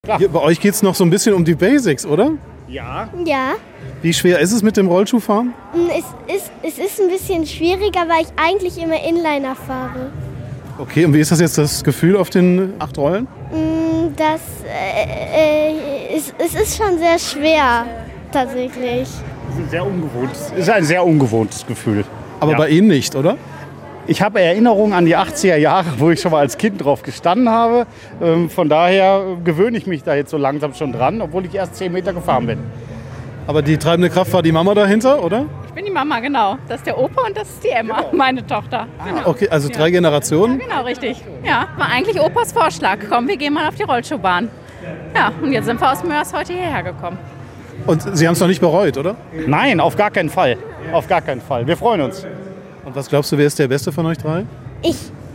Mit Opa, Tochter und Enkelin sind es sogar drei Generationen.